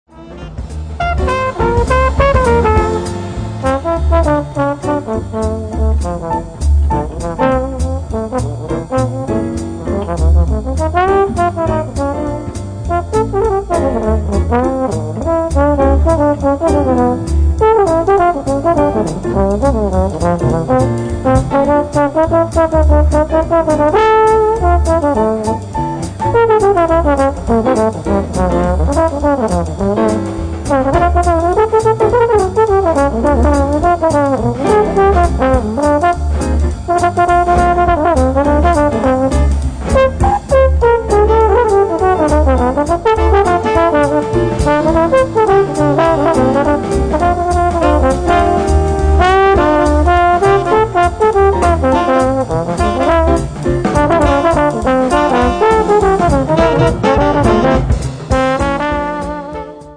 tenor sax & clarinet